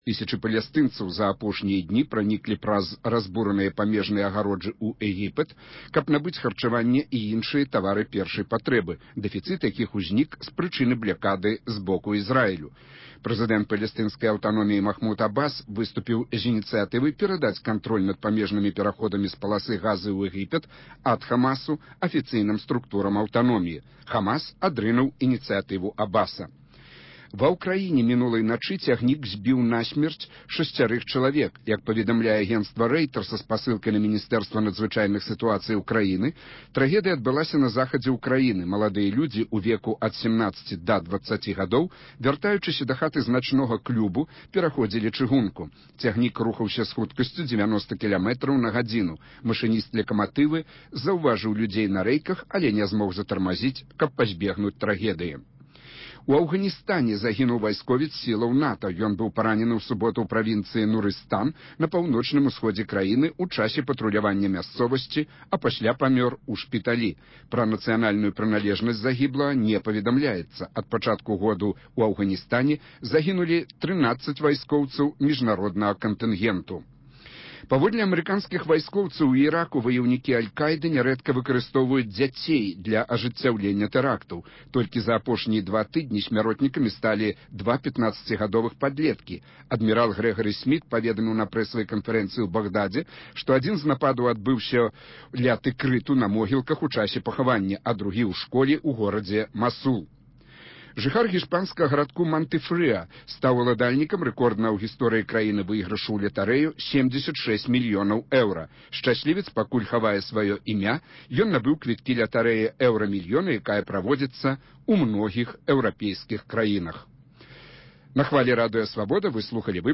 Як суадносяцца свабода слова і цярпімасьць да рэлігійных поглядаў іншых? Талерантнасьць – унівэрсальная каштоўнасьць ці спараджэньне хрысьціянскага сьвету? Ці трэба талераваць неталерантныя погляды і падыходы? На гэтыя тэмы ў “Праскім акцэнце” вядуць дыскусію